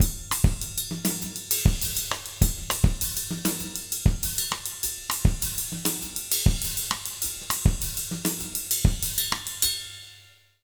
Index of /90_sSampleCDs/USB Soundscan vol.08 - Jazz Latin Drumloops [AKAI] 1CD/Partition E/03-100MOZAMB